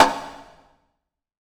HCONGASL.wav